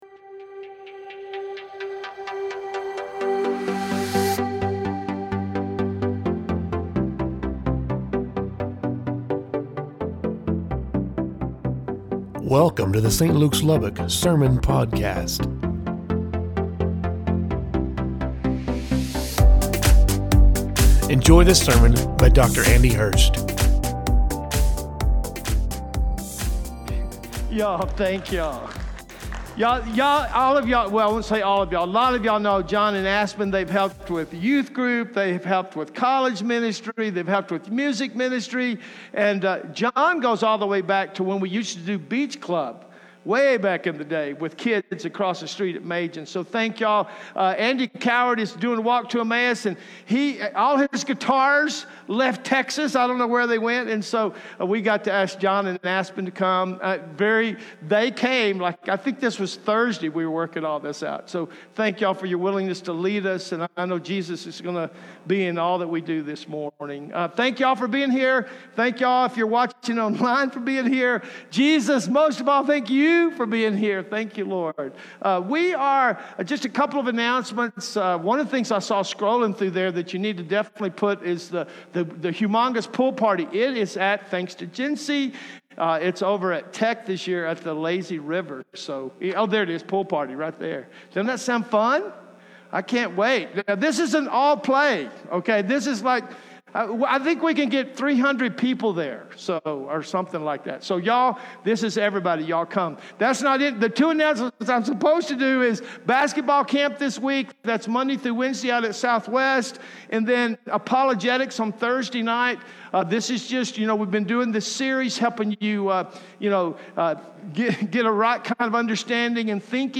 Service Type: Central Campus